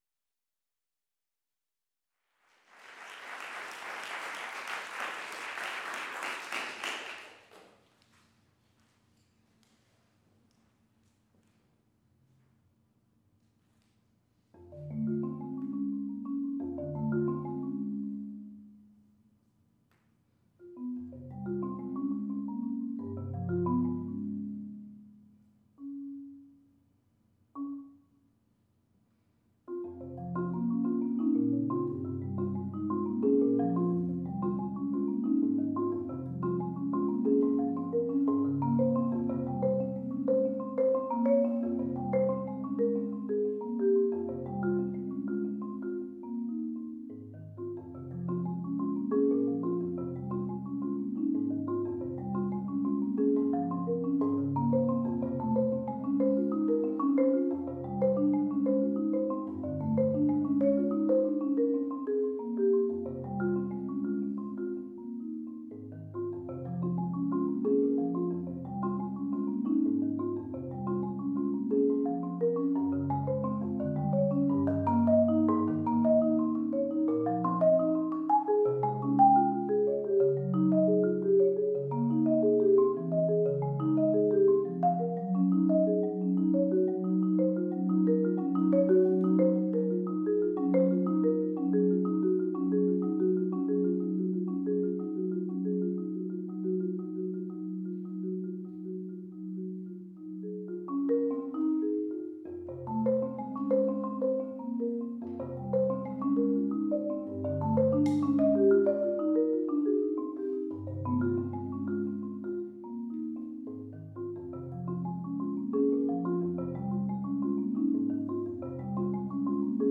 Tango," from From My Little Island by Robert Aldridge; performed live at my senior/Performance Certificate recital, December 7, 2007, at The University of Texas at Austin